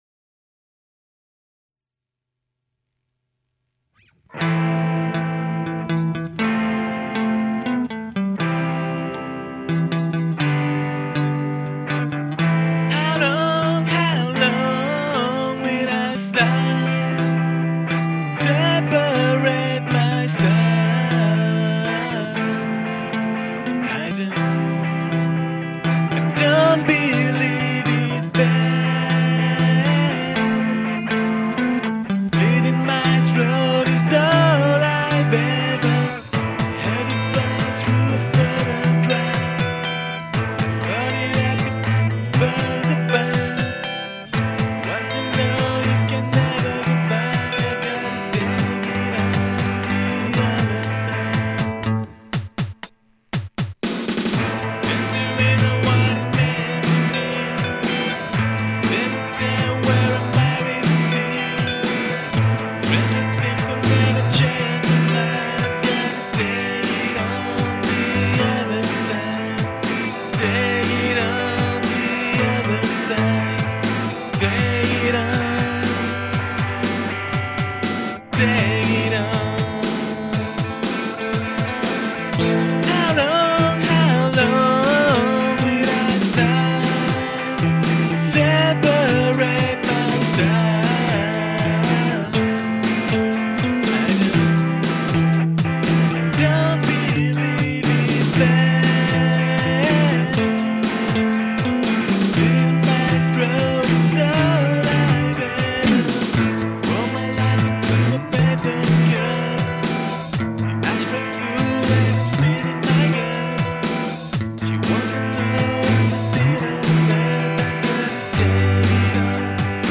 Mais pas à chanter.
Guitare principale
Batterie : Guitar Pro 4